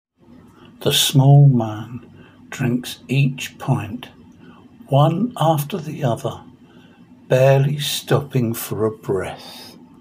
Each line is written in English and then in Spanish and has a recording of me reading it.